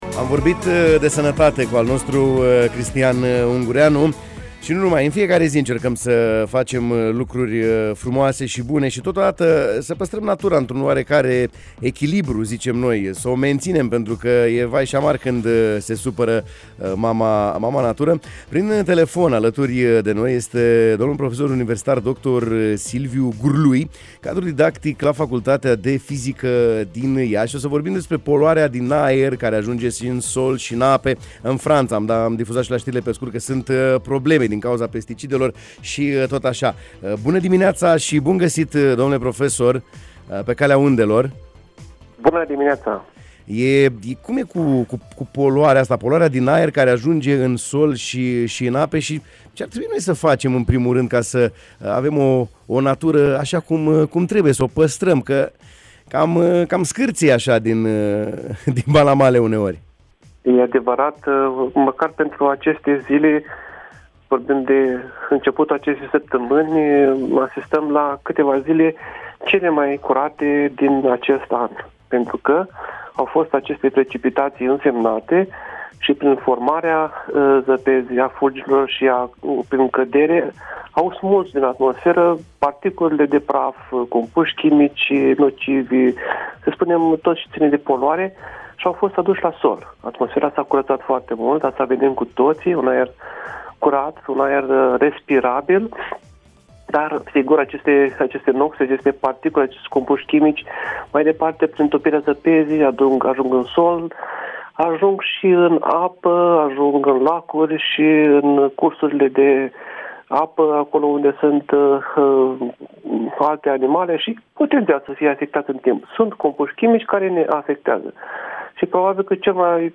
în direct, prin telefon la matinalul de la Radio România Iași: